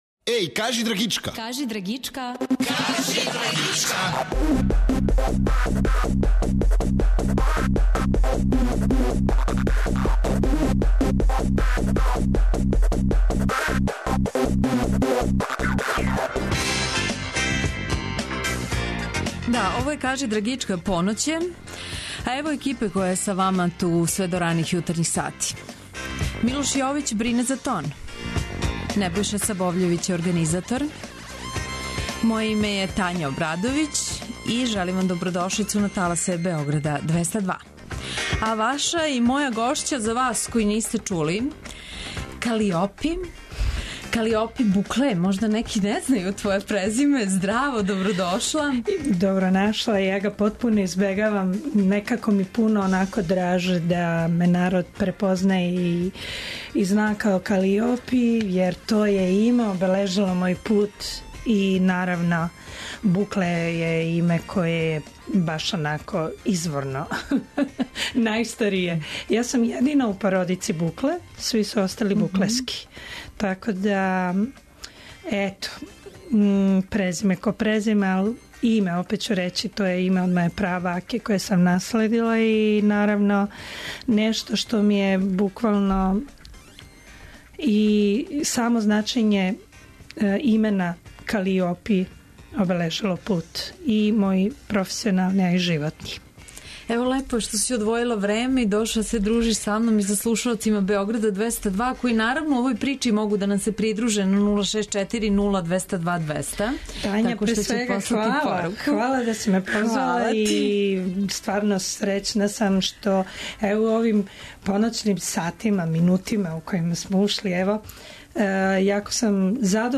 Гост емисије је македонска певачица Калиопи, један од најпознатијих вокала балканске музичке сцене, препознатљива по свом специфичном гласу.